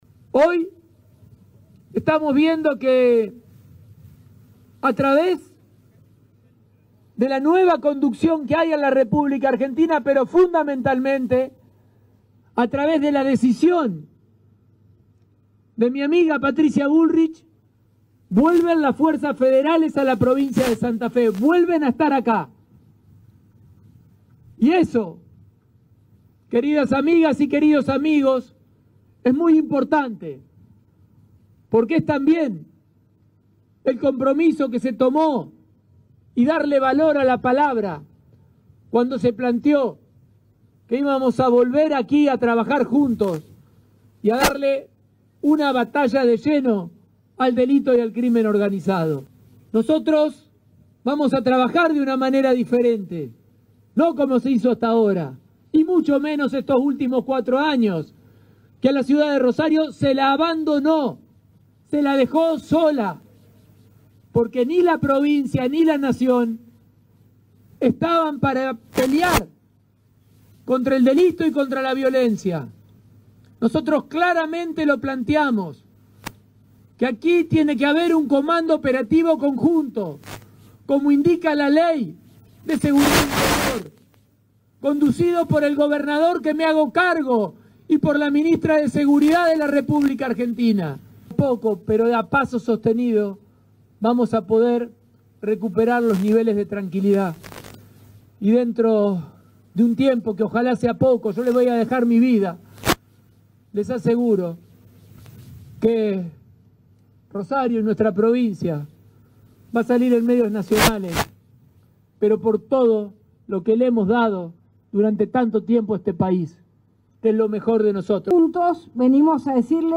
Con un acto que se realizó esta tarde en el Monumento a la Bandera, ante la formación de efectivos de fuerzas de seguridad provinciales y nacionales, el gobernador Maximiliano Pullaro y la ministra de Seguridad de la Nación, Patricia Bullrich pusieron en marcha una serie de acciones y medidas coordinadas para luchar contra el delito.